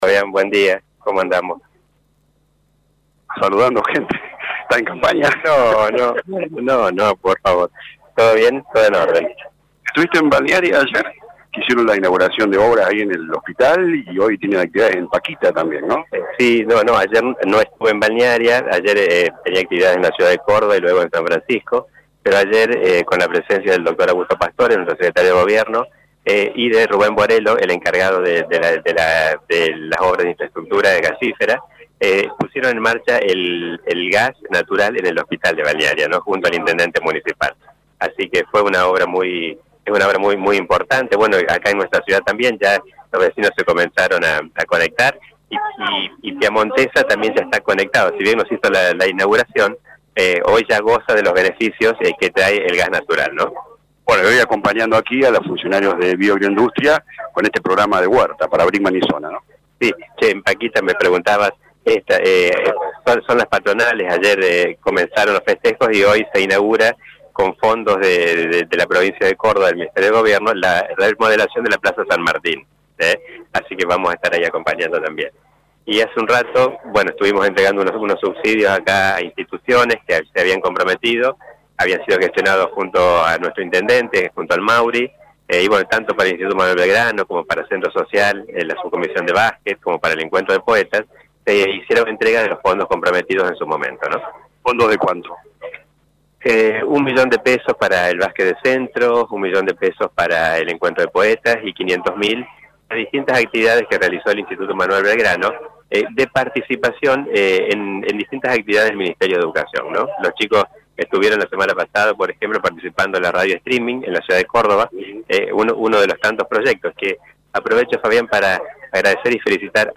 Tevez habló con LA RADIO 102.9 sobre obras en las rutas de la zona, la conexión del gas natural en el Hospital de Balnearia y la Plaza en La Paquita…
AUDIO LEGISLADOR GUSTAVO TEVEZ